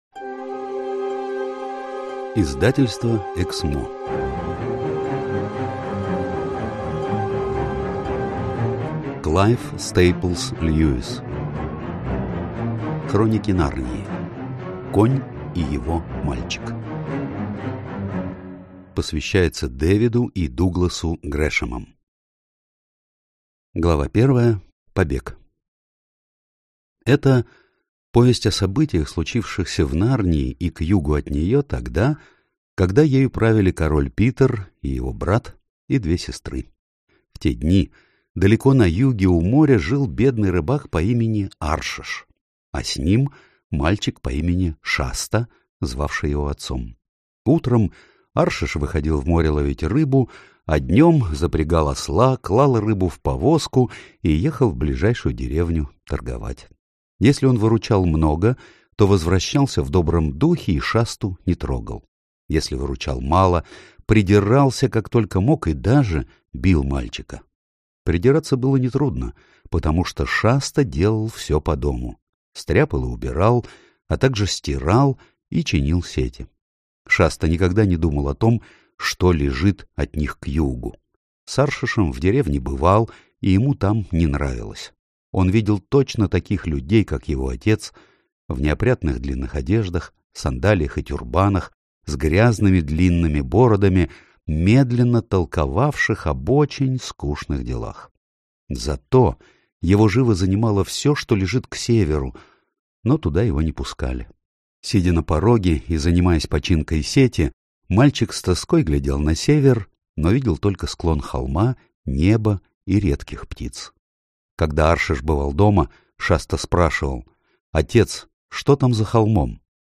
Аудиокнига Конь и его мальчик | Библиотека аудиокниг